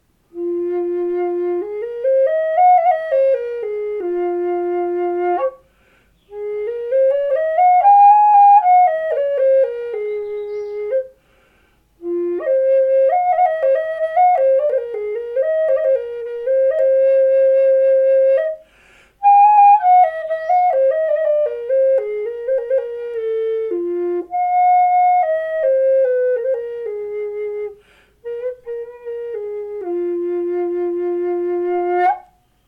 F4 KOA turtle wave 2.mp3